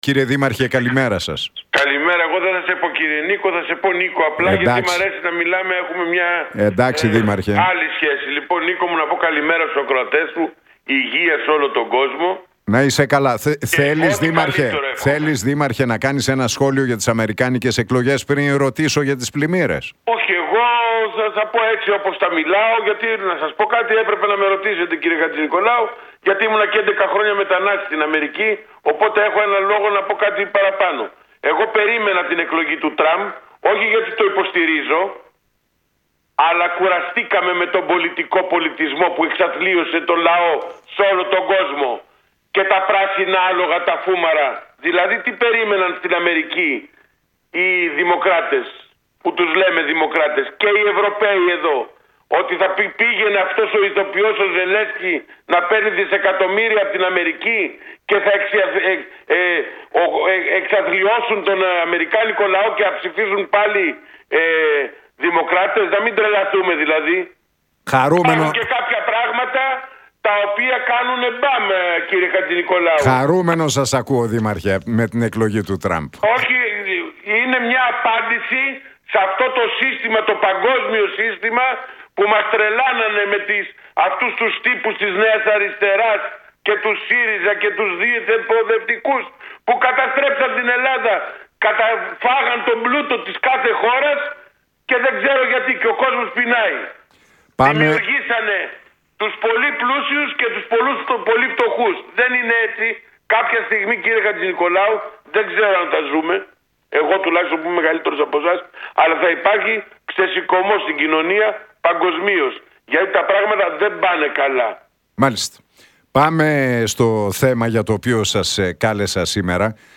Την εκλογή του Ντόναλντ Τραμπ στις ΗΠΑ σχολίασε ο Αχιλλέας Μπέος στον Realfm 97,8 και την εκπομπή του Νίκου Χατζηνικολάου, ενώ αναφέρθηκε και στα αντιπλημμυρικά έργα.